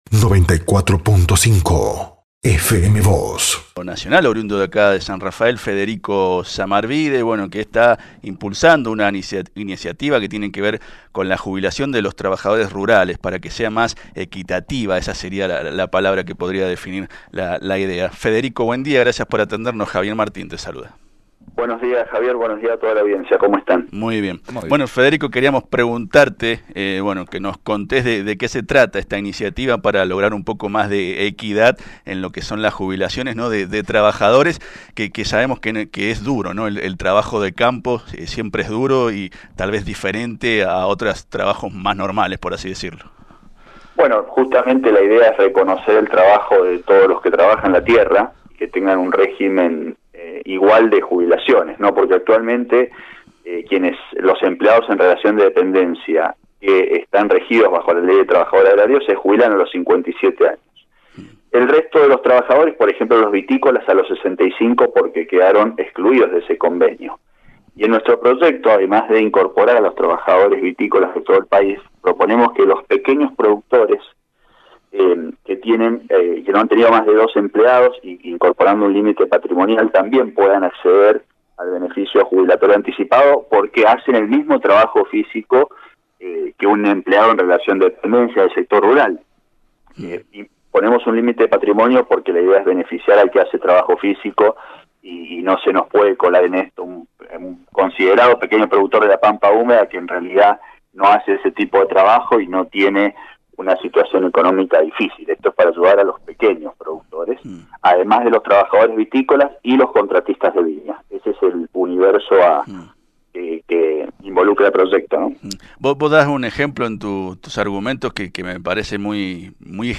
“La idea es reconocer a las personas que trabajan la tierra porque actualmente, los empleados en relación de dependencia en el sector rural se jubilan a los 57 años pero el resto, por ejemplo, los vitícolas y contratistas, se jubilan a los 65 años porque quedaron afueran de este convenio. Además pedimos que obtengan la jubilación anticipada los pequeños productores, que no hayan tenido más de dos empleados y tengan determinado límite de patrimonio, la idea es beneficiar a los pequeños productores”, explicó en FM Vos (94.5) Federico Zamarbide.